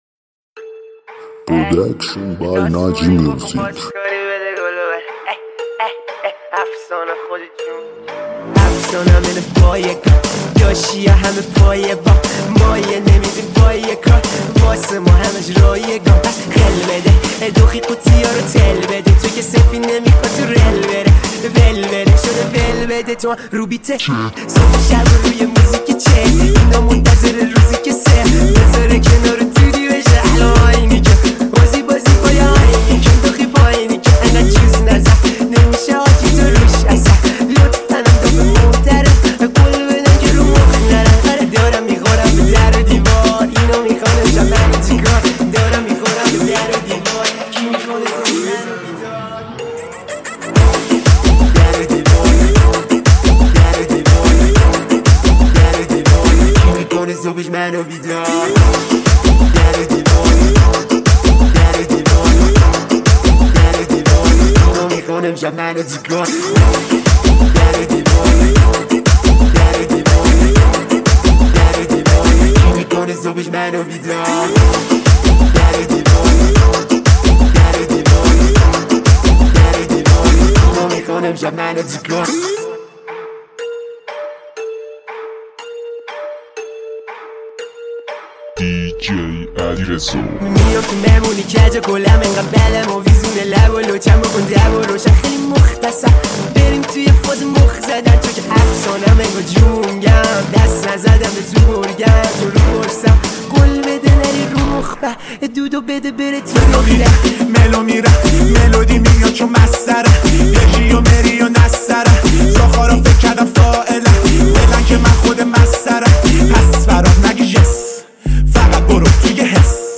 ریمیکس
ریمیکس شاد